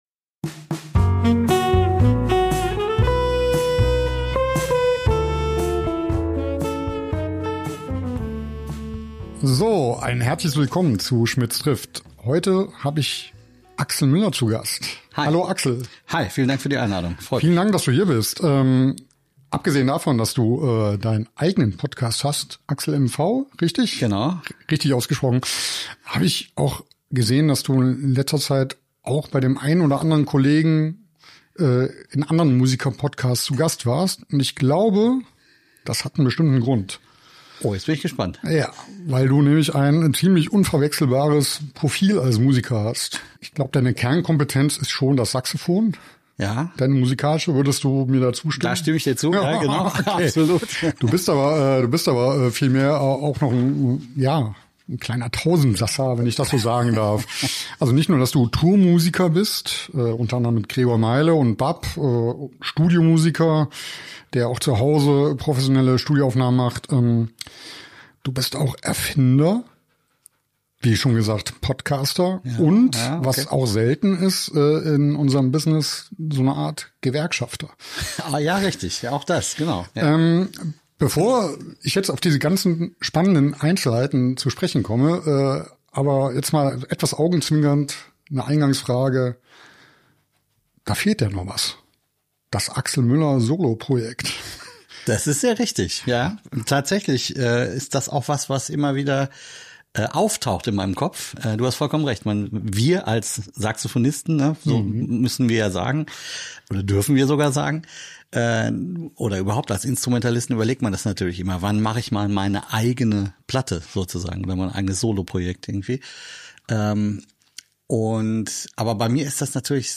Wir unterhalten uns über das Tourleben und wie man sich dabei nicht gegenseitig auf den Nerv geht. Wir sprechen ausserdem über seine Erfindung "Fliphead" und ,last but not least, über die Notwendigkeit, dass sich Musiker*innen organisieren und die damit verbundene Gründung von PRO Musik Verband.